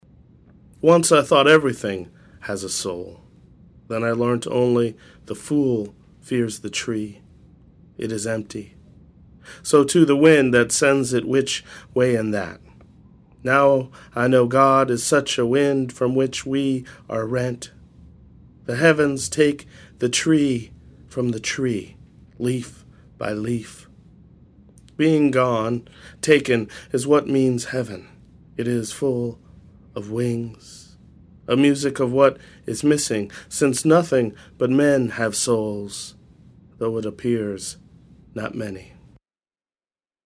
Here is Kevin Young reading “Ash Wednesday” from Ardency.